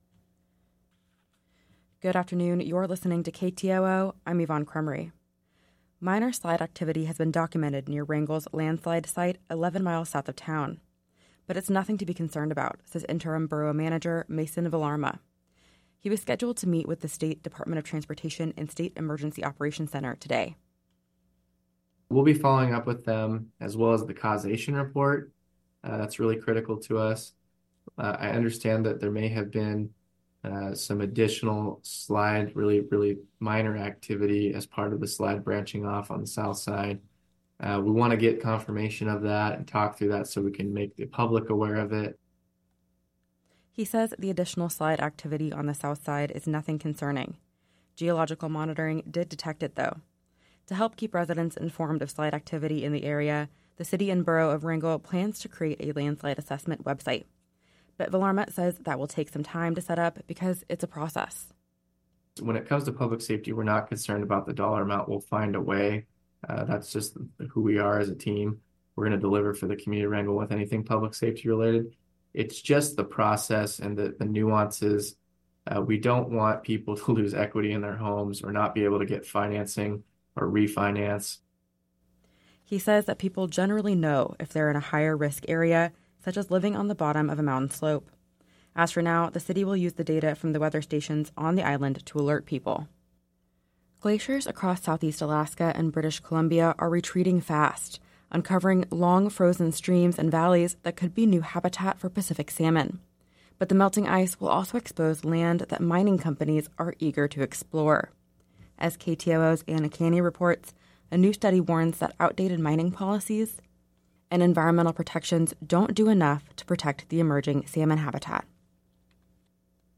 Newscast – Friday, January. 5 2024